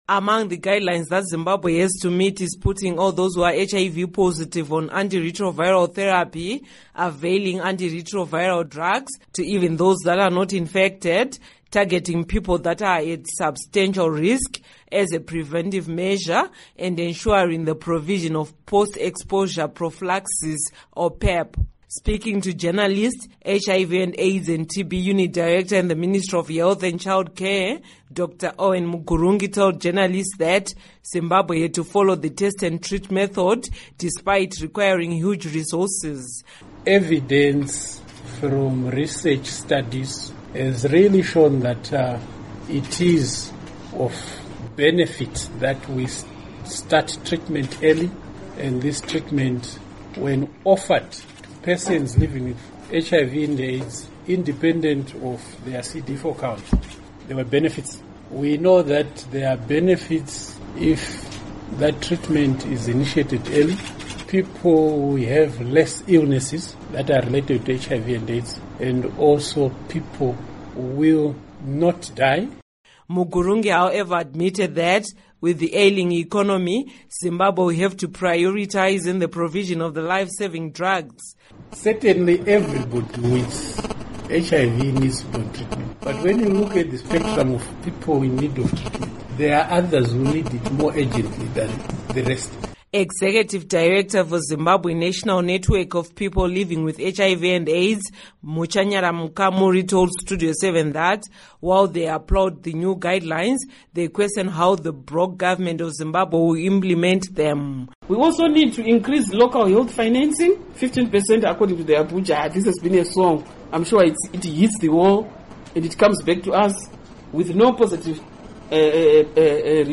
Report on WHO HIV Guidelines